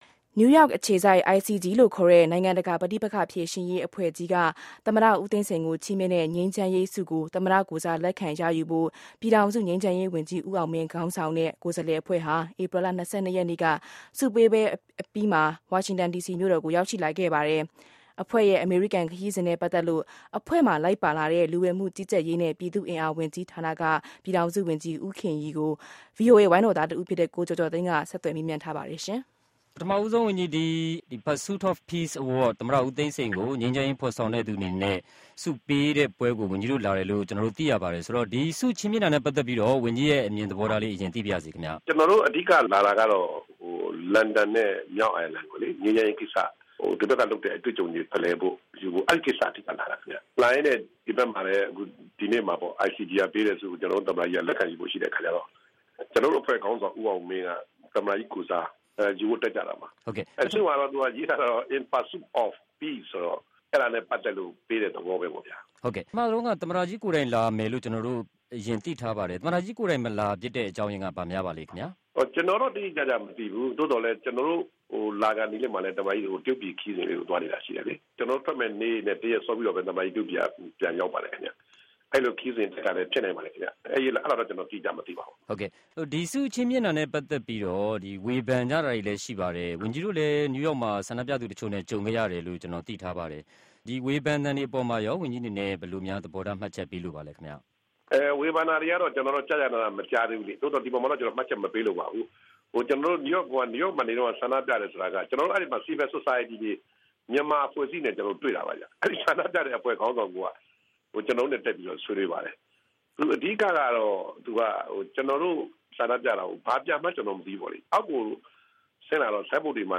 ၀န်ကြီးဦးခင်ရီ အင်တာဗျူး